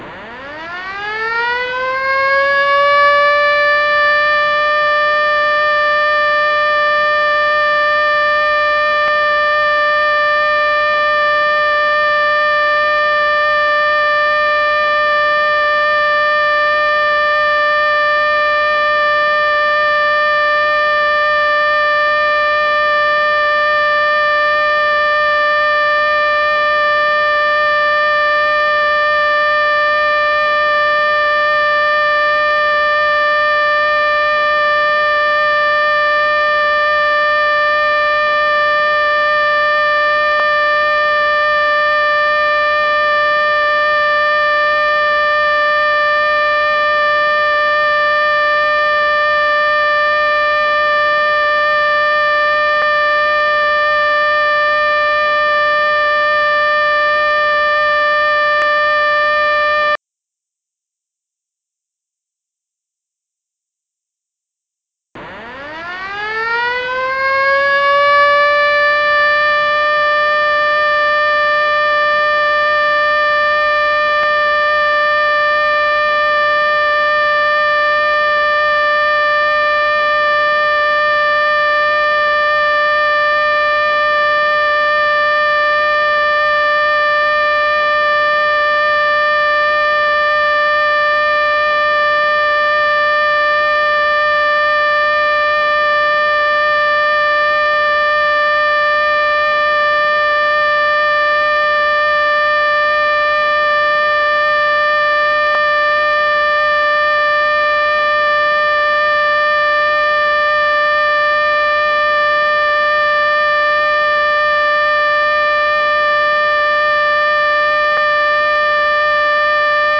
災害が発生した場合又は発生のおそれがある場合には、市内の92か所に設置した屋外拡声子局を通じ、災害状況や避難指示など災害情報の提供を行います。
「避難情報サイレン」の場合
ウ～（1分）、5秒間休止、ウ～（1分）その後「避難情報」を放送します。
1. サイレン信号（ウーというサイレン音）を1分放送、5秒間休止、再度サイレン信号を1分放送した後、避難情報を放送する。
避難情報サイレンサンプル音 (音声ファイル: 2.0MB)
Suzaka_hinan_Siren.mp3